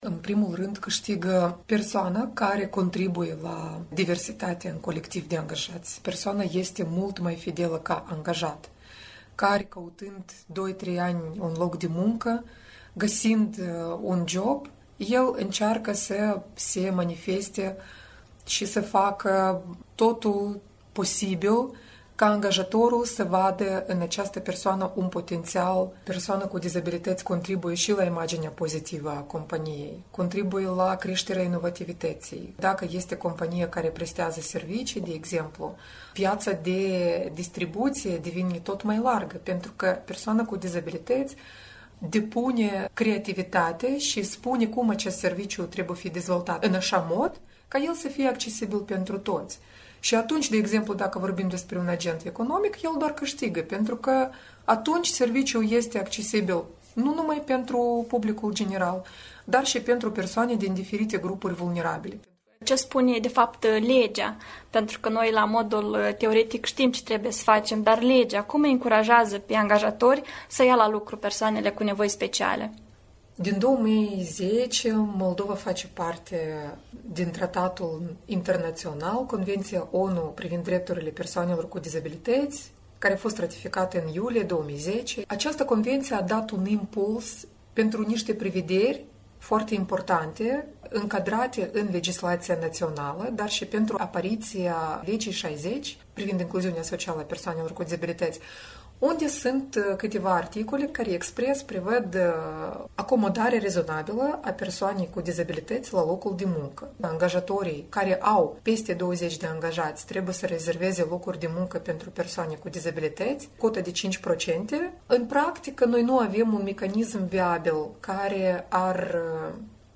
Un interviu cu experta în domeniul drepturilor omului